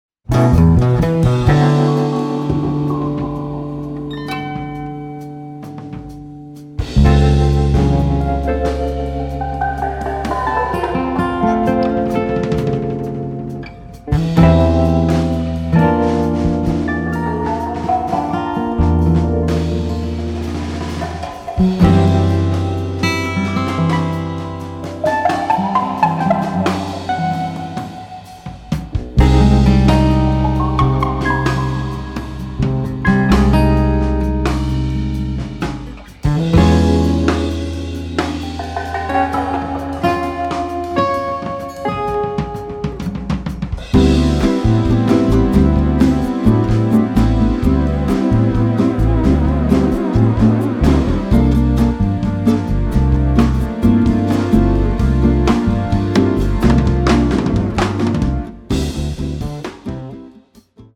guitars
bass
drums
keyboards
tabla
Mongolian throat singing
percussion
Hindustani vocal